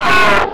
lava2.wav